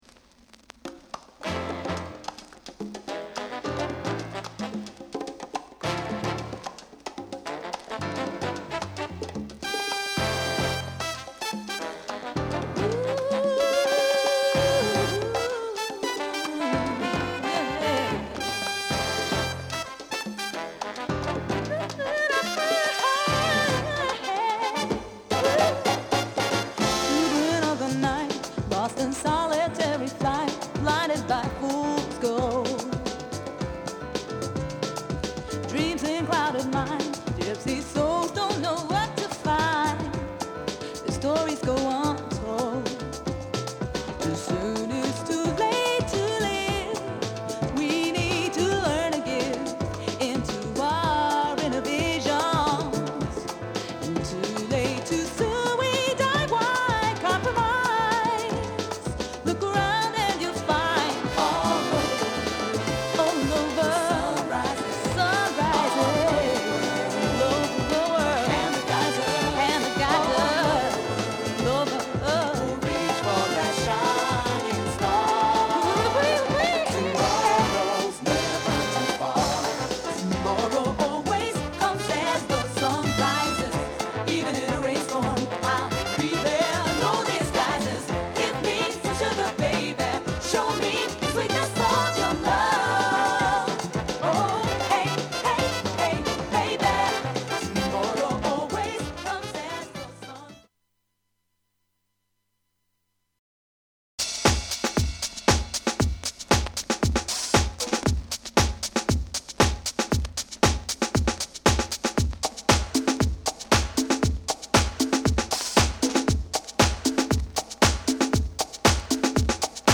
DANCE / SOUL / HOUSE / LATIN